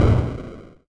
Techmino/SFX/fall.ogg at 6a80cdc4b996c19e697f2c4a4ef07991e556cacc
fall.ogg